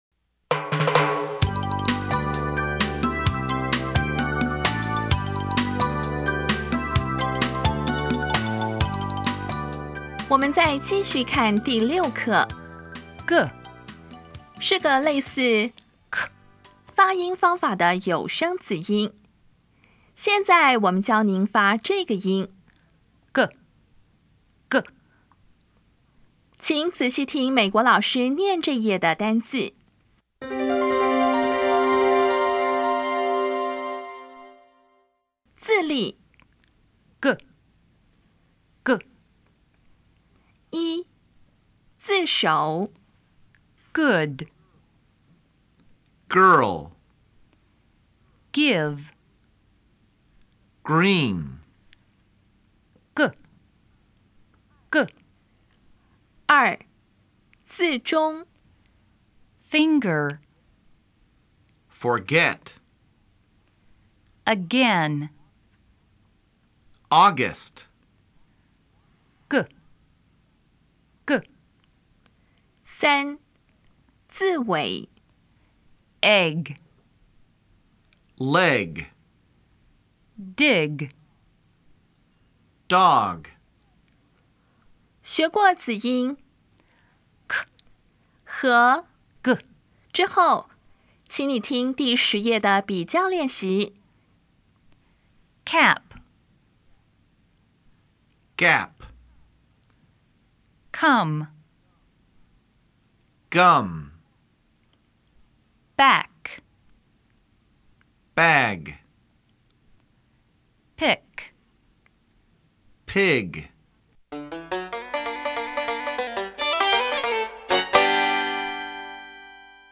当前位置：Home 英语教材 KK 音标发音 子音部分-2: 有声子音 [g]
音标讲解第六课
比较[k][g]     [k](无声) [g] (有声)
Listening Test 3